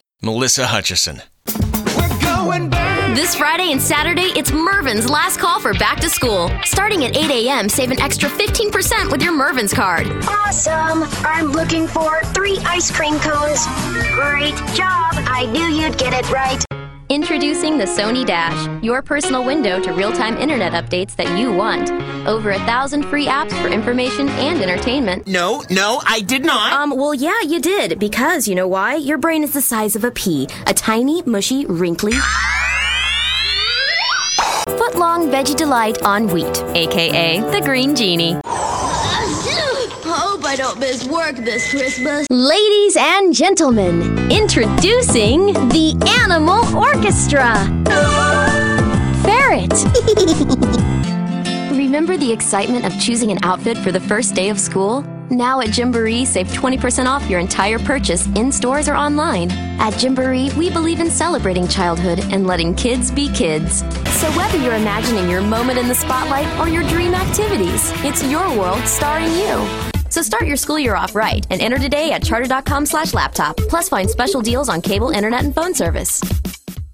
Demos
Female Commercial Demo